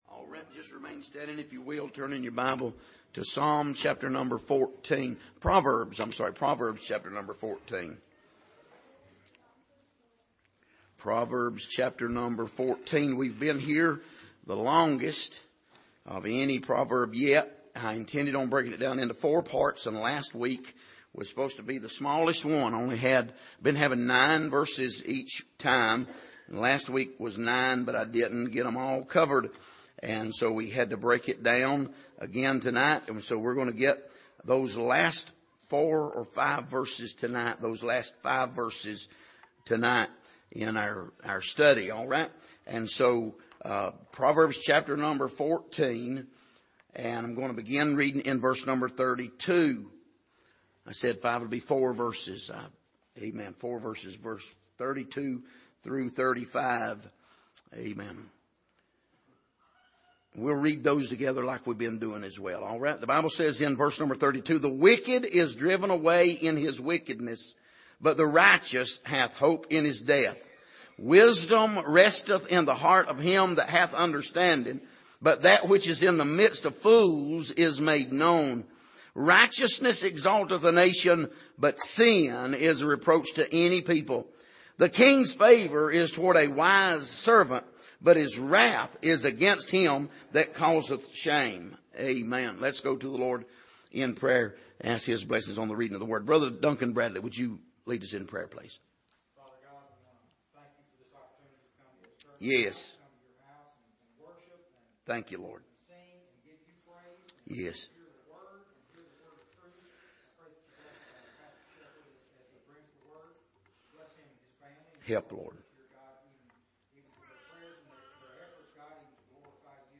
Here is an archive of messages preached at the Island Ford Baptist Church.
Service: Sunday Morning